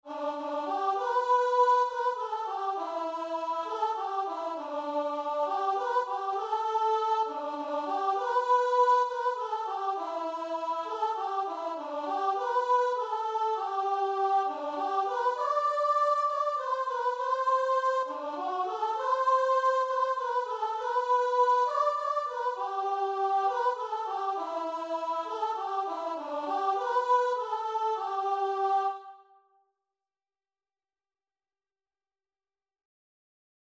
Christian Christian Guitar and Vocal Sheet Music Higher Ground
Free Sheet music for Guitar and Vocal
G major (Sounding Pitch) (View more G major Music for Guitar and Vocal )
3/4 (View more 3/4 Music)
Classical (View more Classical Guitar and Vocal Music)